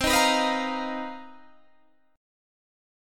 Listen to BM7b5/C strummed